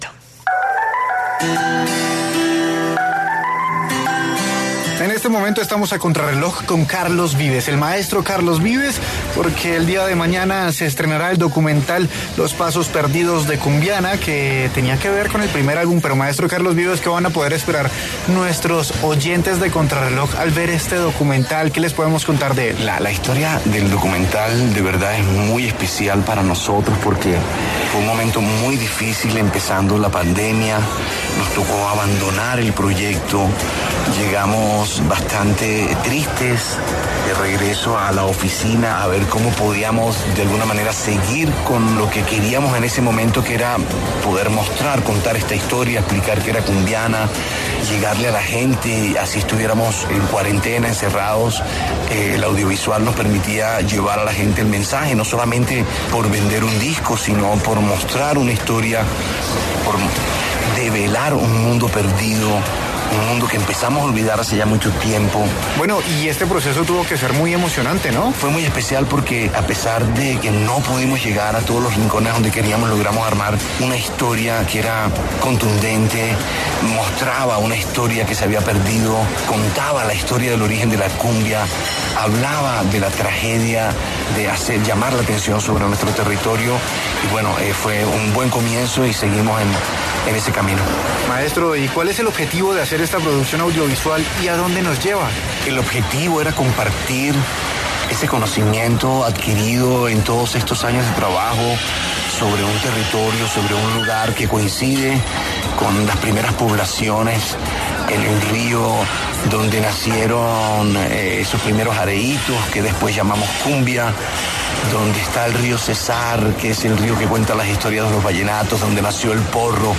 Carlos Vives habló a Contrarreloj sobre su nuevo documental que presentará este viernes 24 de junio.